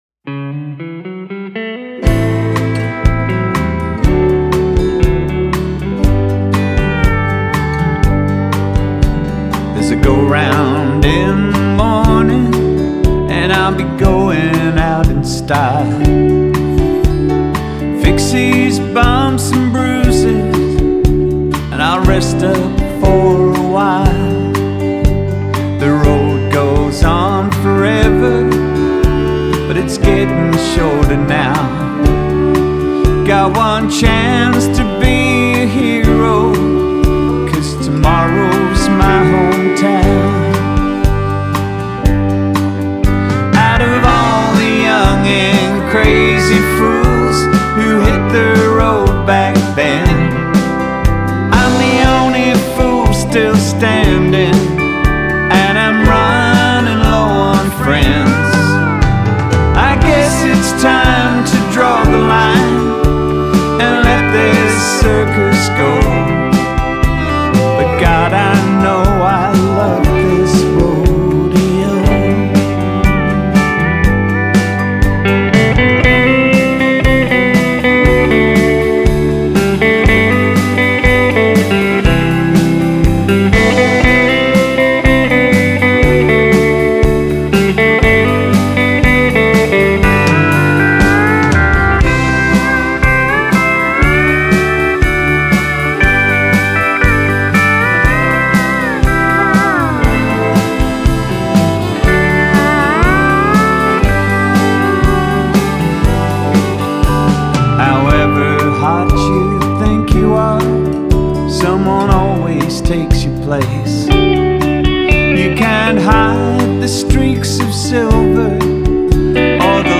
vocals and harmonies
pedal steel
fiddle
piano
classic country music